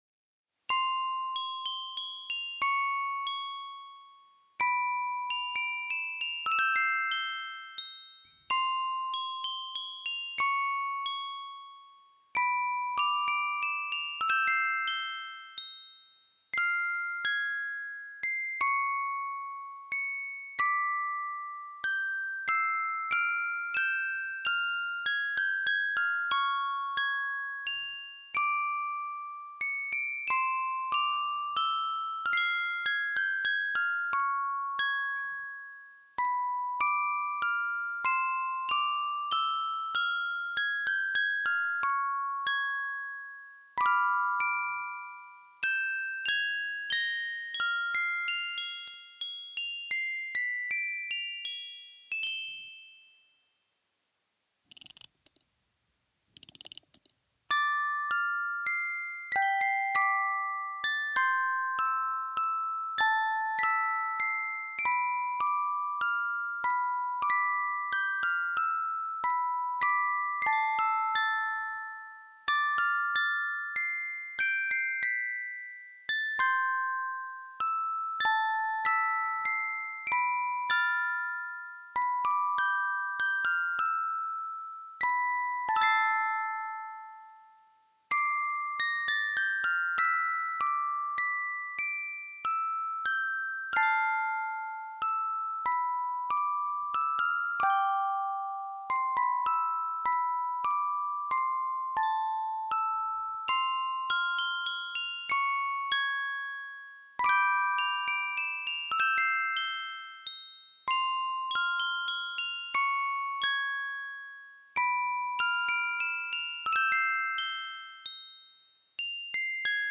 Здесь собраны лучшие композиции с нежным механическим звучанием, которые подойдут для релаксации, творчества или создания особой атмосферы.
Мелодичный звук музыкальной шкатулки